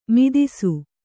♪ midisu